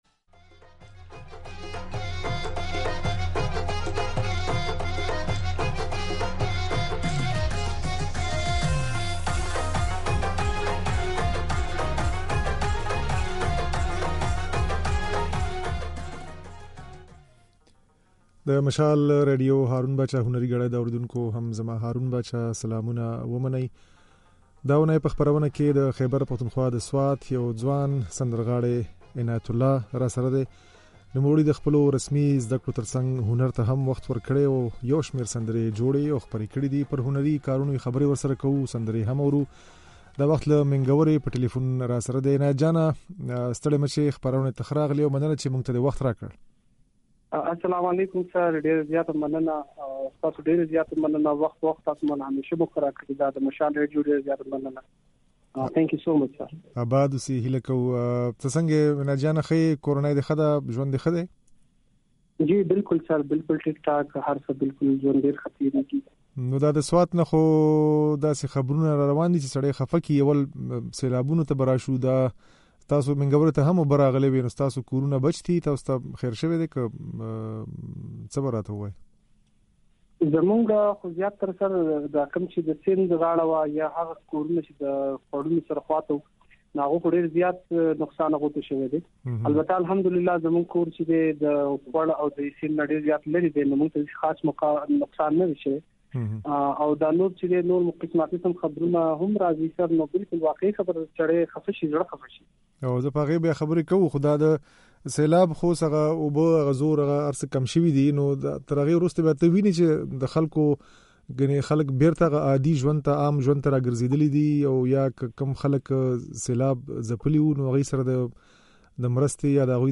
ځينې نوې سندرې يې د غږ په ځای کې اورېدای شئ